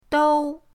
dou1.mp3